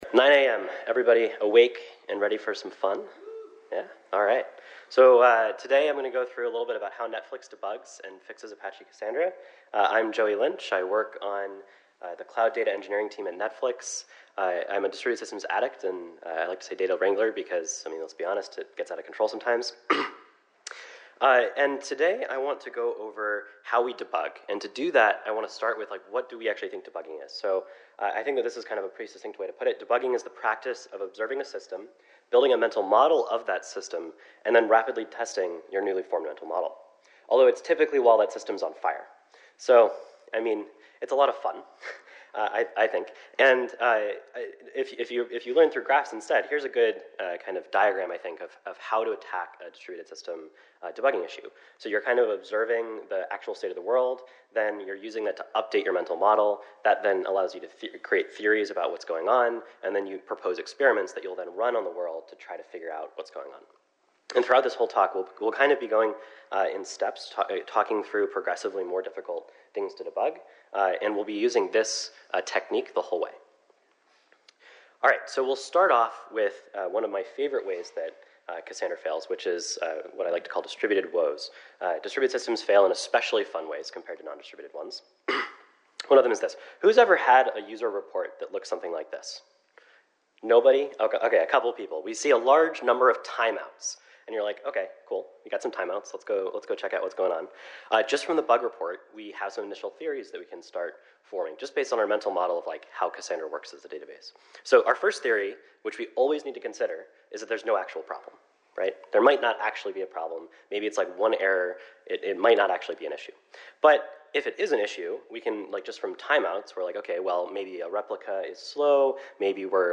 Netflix relies on Apache Cassandra as a critical source of truth database, and while Cassandra is a remarkably resilient database, it does, ever so occasionally, break. This talk explores how complex Cassandra deployments fail in production, but more importantly, the techniques, tools, and approaches our distributed systems engineers use to debug and mitigate these failures.…